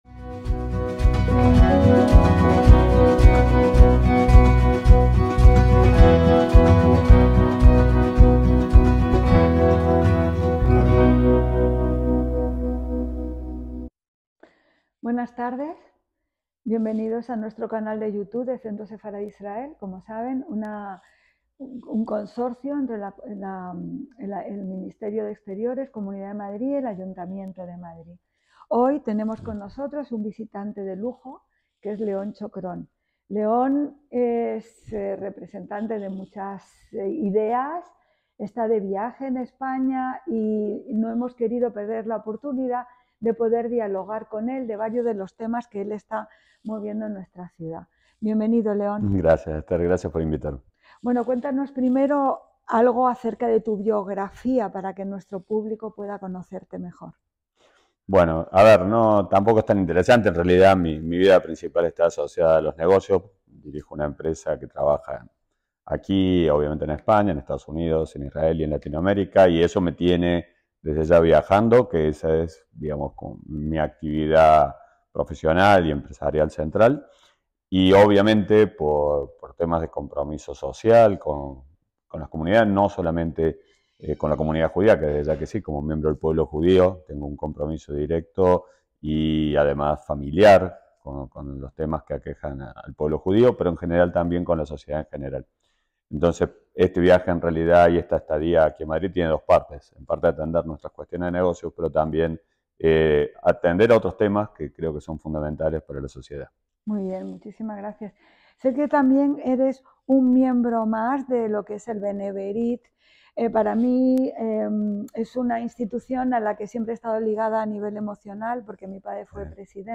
Conversación
ACTOS EN DIRECTO